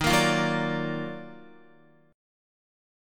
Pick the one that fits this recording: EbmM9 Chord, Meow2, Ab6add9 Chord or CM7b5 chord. EbmM9 Chord